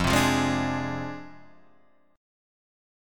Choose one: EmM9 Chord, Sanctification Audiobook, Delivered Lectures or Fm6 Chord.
Fm6 Chord